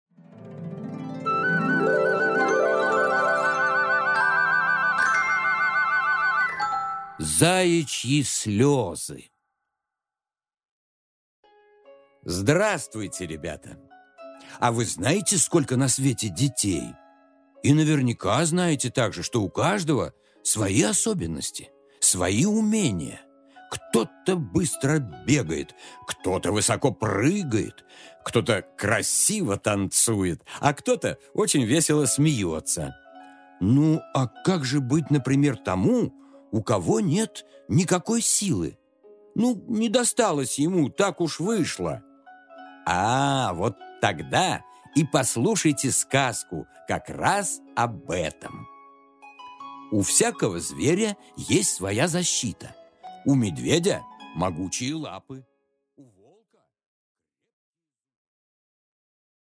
Аудиокнига Заячьи слёзы | Библиотека аудиокниг